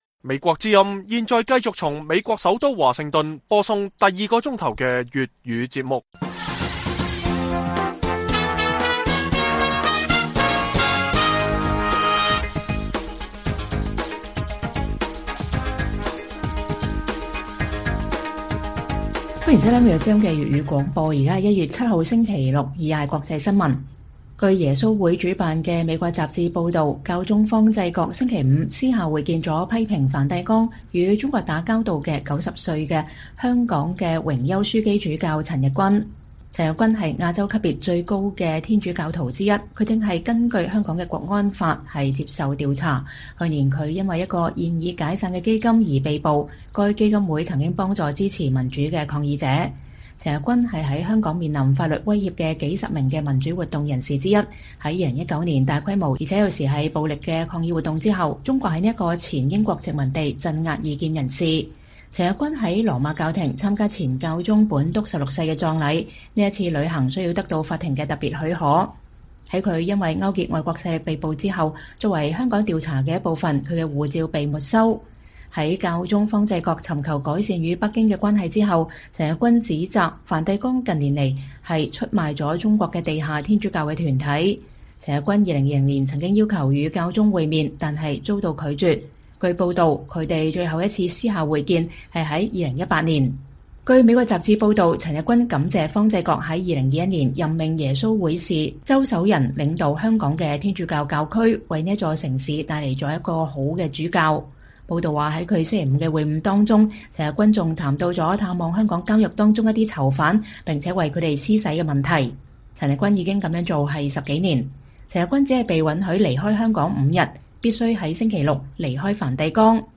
粵語新聞 晚上10-11點: 報導：陳日君在梵蒂岡私下拜會了教宗方濟各